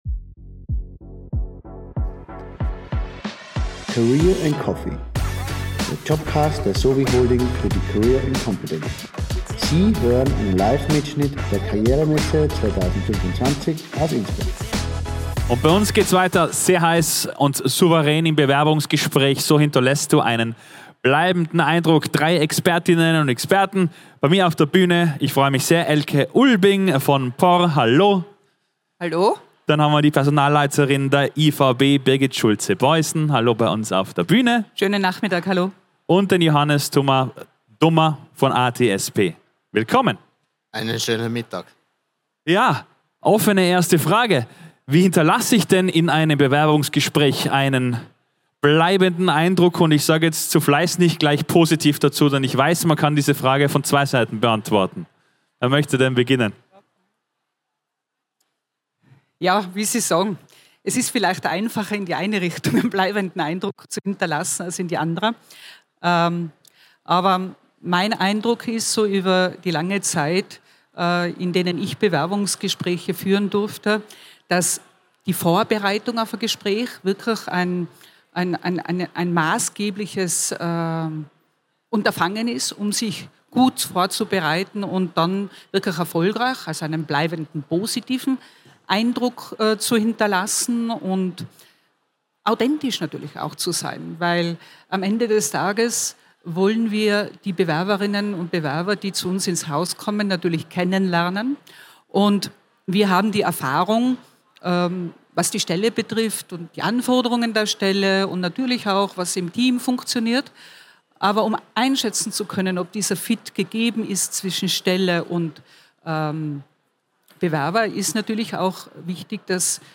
Livemitschnitt #6 von der career & competence am 14. Mai 2025 im Congress Innsbruck.